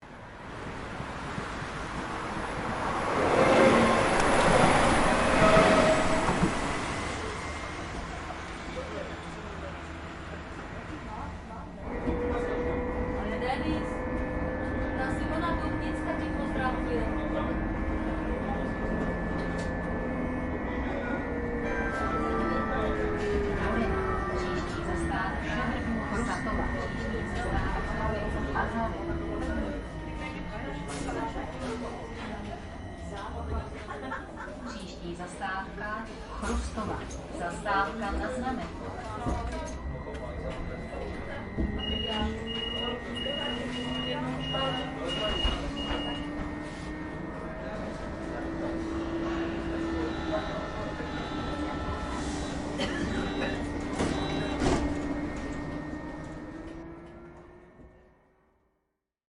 Riding the MagLev.mp3